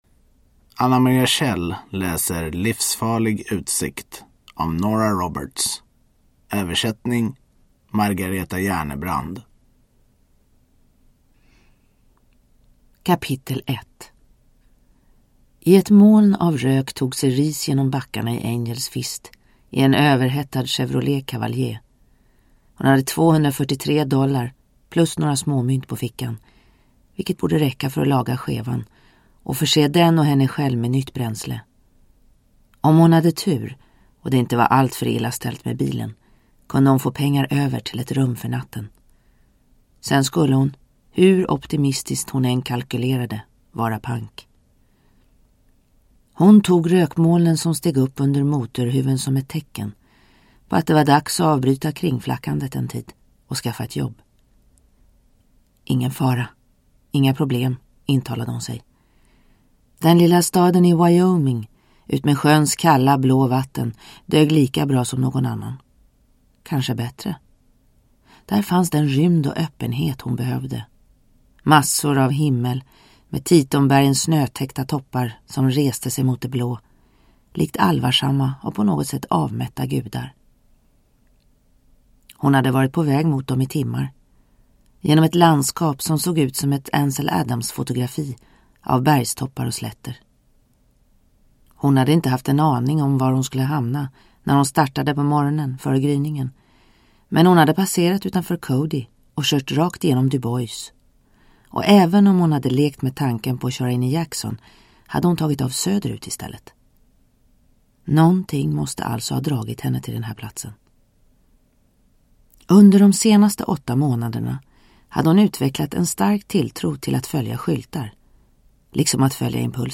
Livsfarlig utsikt – Ljudbok – Laddas ner